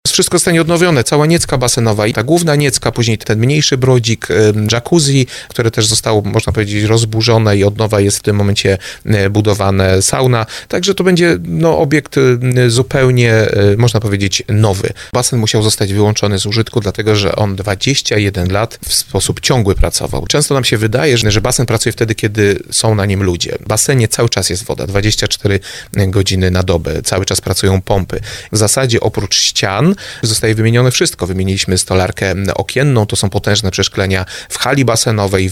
Burmistrz Tomasz Latocha mówił w audycji Słowo za Słowo, że remontu tego obiektu nie było od 20 lat.